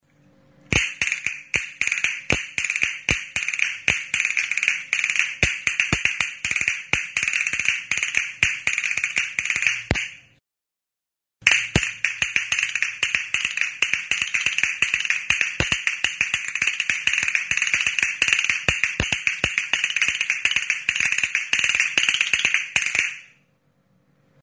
Instrumentos de músicaCASTAÑUELAS
Idiófonos -> Golpeados -> Indirectamente
Grabado con este instrumento.
CASTAÑUELAS